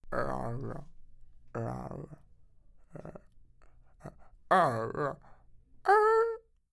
机关枪射击4
描述：机枪声，由Soundforge制造，带有FM合成。
标签： 喷枪触发 机枪 弗利
声道立体声